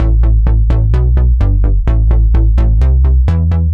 Index of /musicradar/french-house-chillout-samples/128bpm/Instruments
FHC_NippaBass_128-A.wav